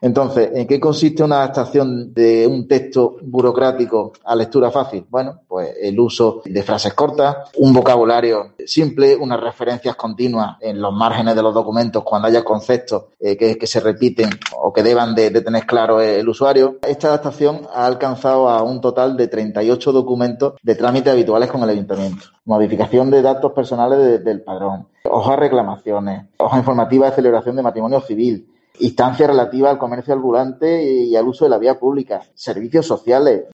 En una rueda de prensa, el concejal ha defendido "la accesibilidad universal" y ha subrayado que dicha lectura fácil tiene "una repercusión bastante más amplia de lo que inicialmente se esperaba", al estar dirigida "no sólo para un público con alguna discapacidad".